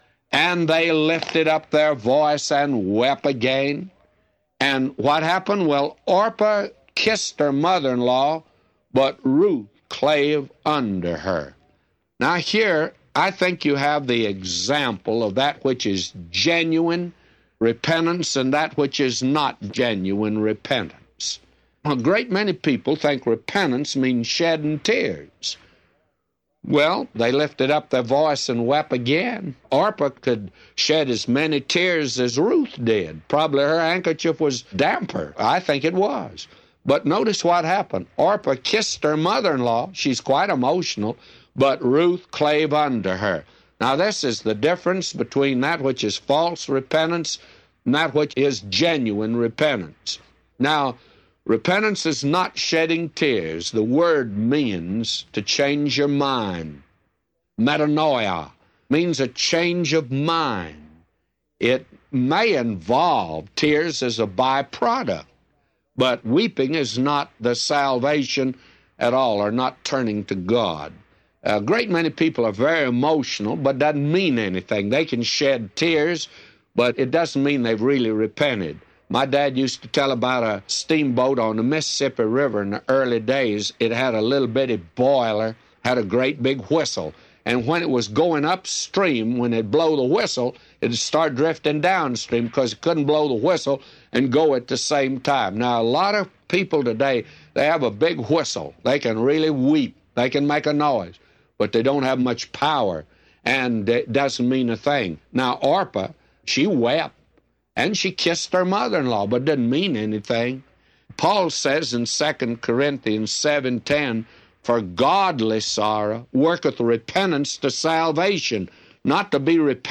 Does shedding tears mean repentance? Both Ruth and Orpah shed tears. Only one repented. Dr. McGee explains this from one of broadcasts in Ruth.